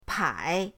pai3.mp3